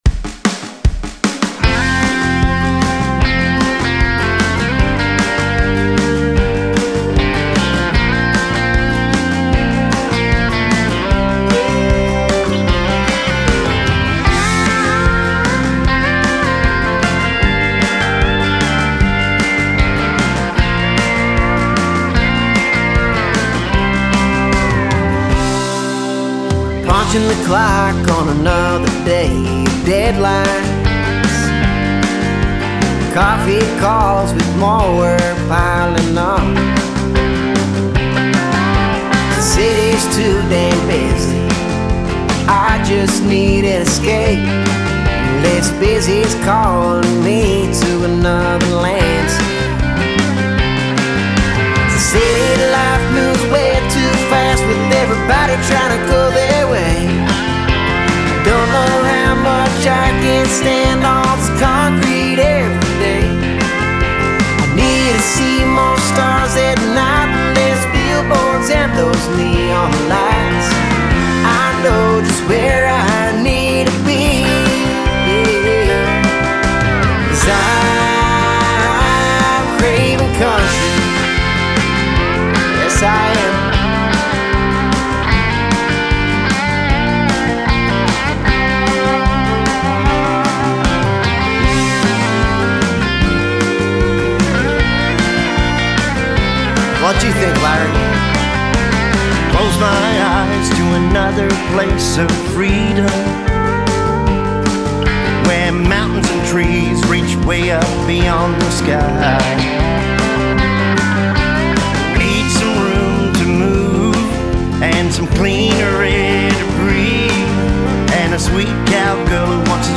catchy track